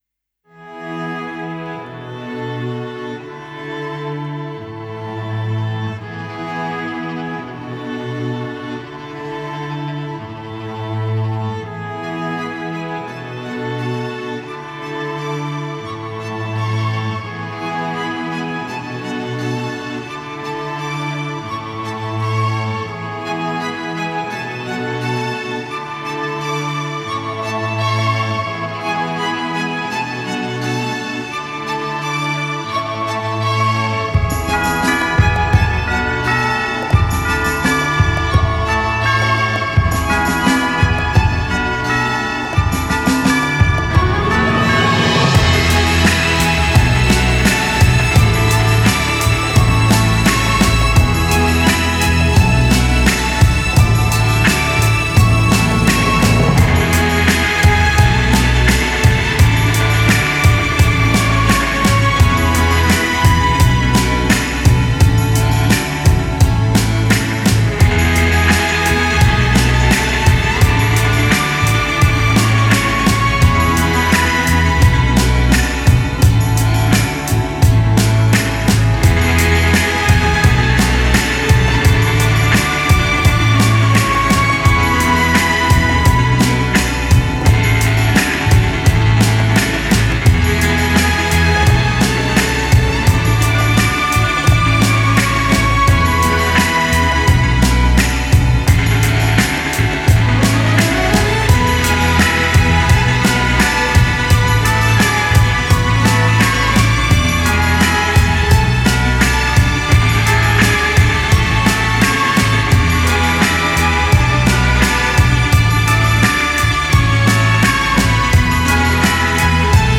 электронный струнный квартет из Лондона, Великобритания
Genre: Electronic, Classical